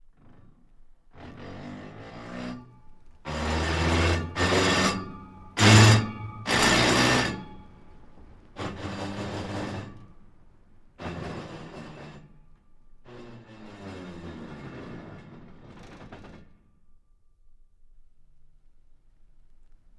Car Transmission Manual; By With Very Bad Gear Grinds, No Motor 3x